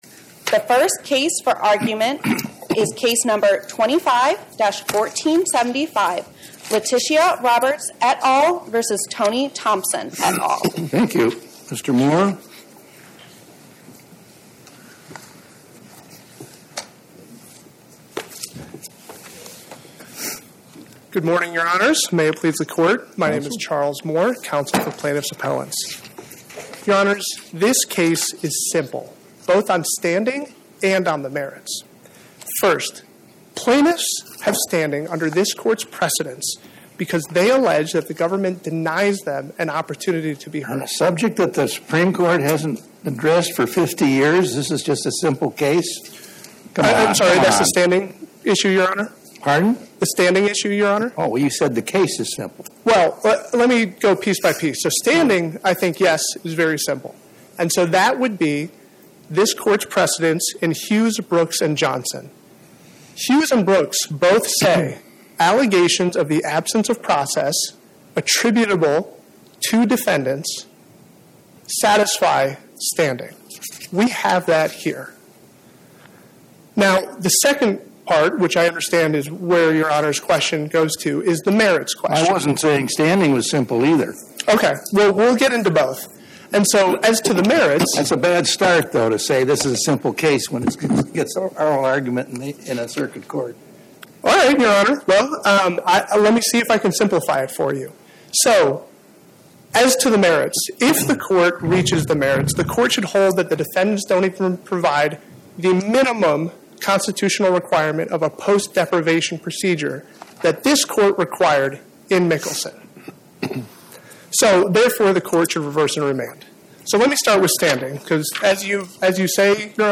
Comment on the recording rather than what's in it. Oral argument argued before the Eighth Circuit U.S. Court of Appeals on or about 01/15/2026